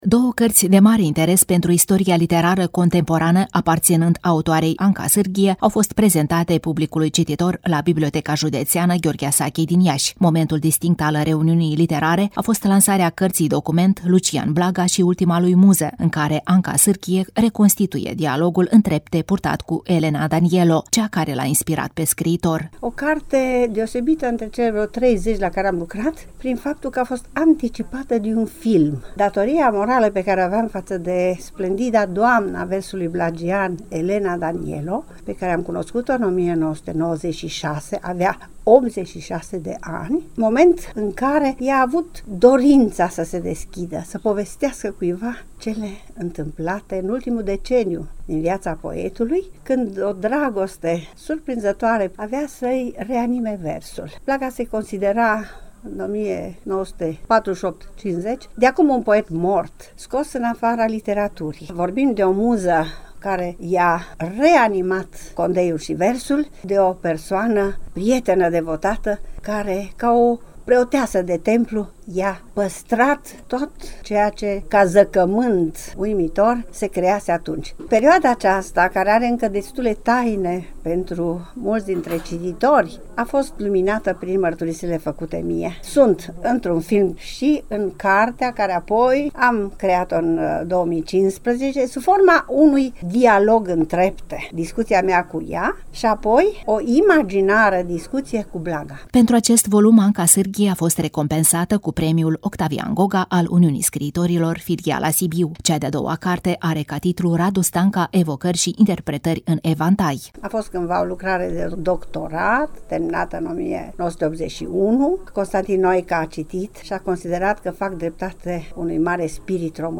Manifestarea a inclus și un recital de versuri din creația celor doi poeți, susținut de elevi de la Liceul „Petru Poni” din Iași.